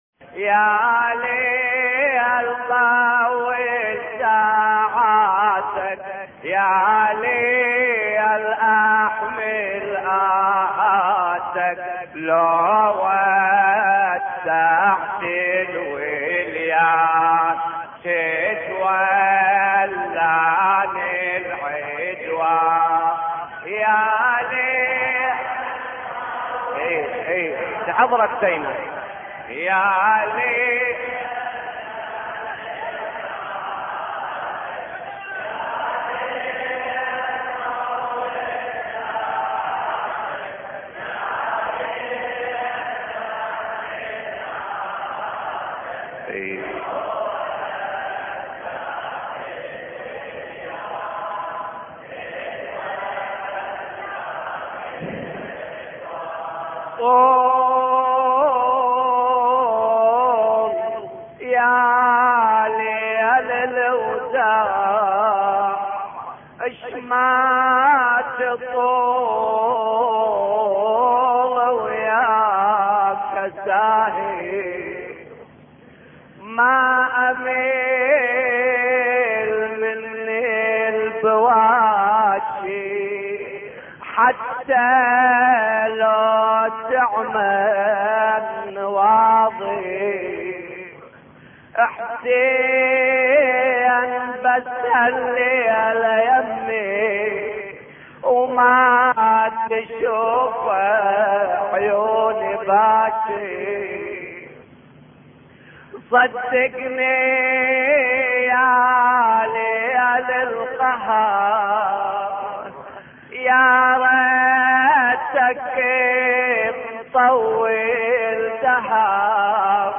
مراثي الامام الحسين (ع)